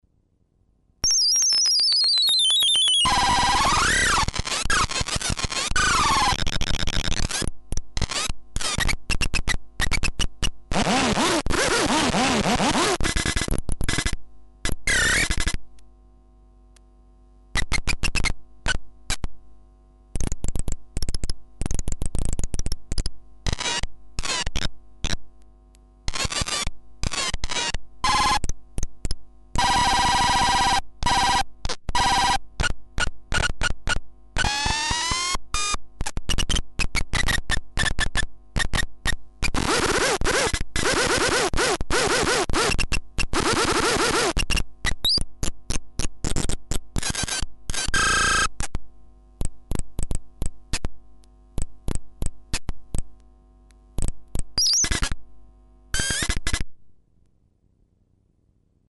This is a kid's sound maker (mostly animal noises) ripped up and rehoused in a wooden box. Two banks of nine sounds activated by pressing the pins with the stylus on the end of the wire and a pitch control.